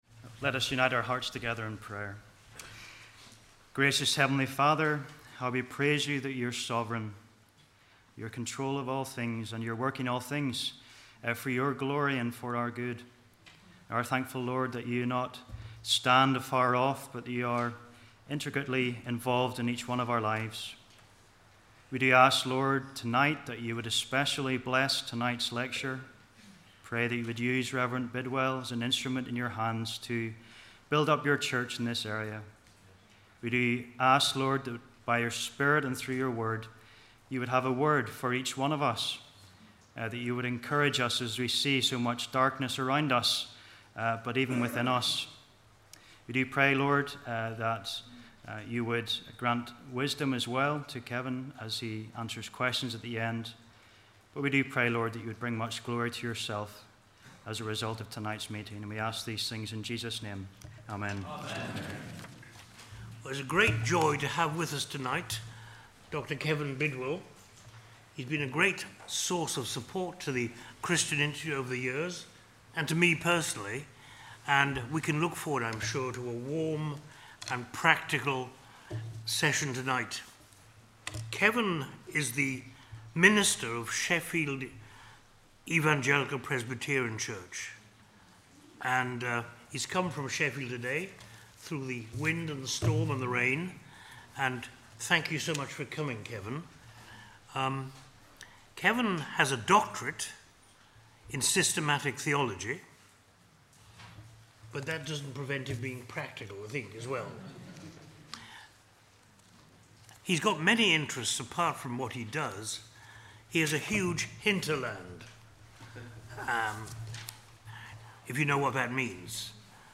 2016 Autumn Lectures